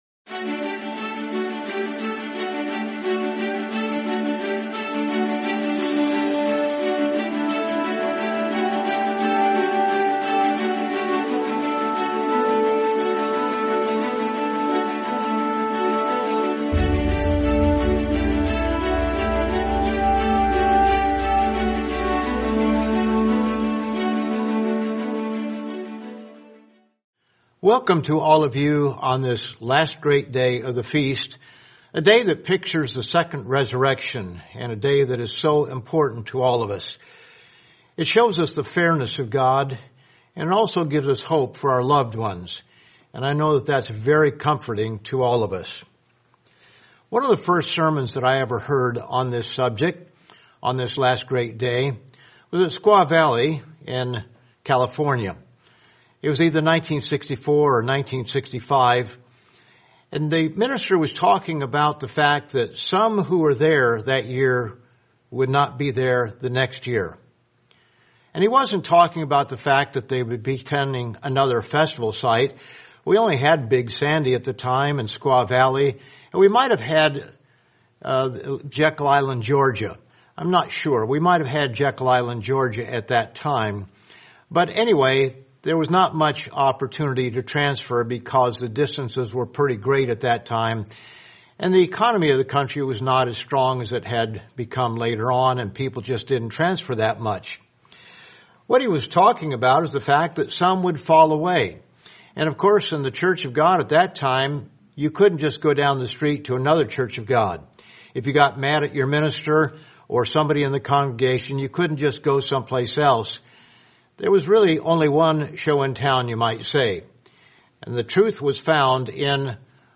Sermon Last Great Day 2025: The Blessing of the First Resurrection